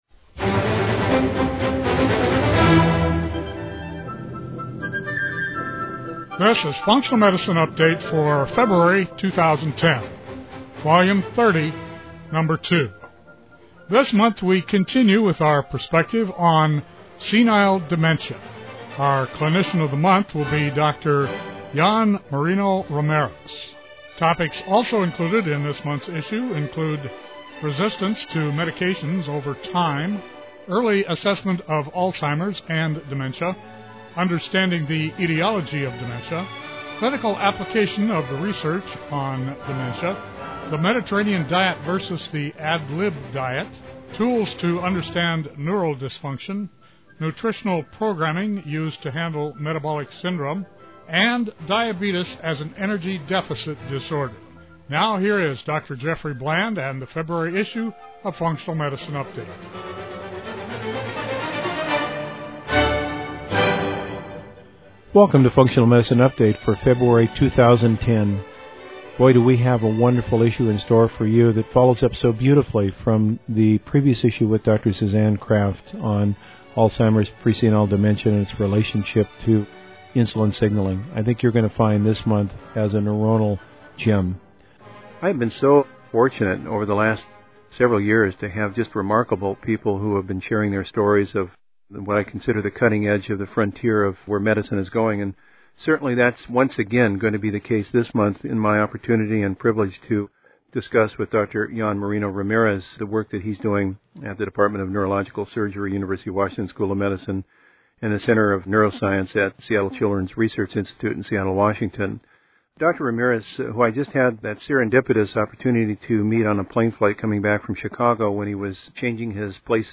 INTERVIEW TRANSCRIPT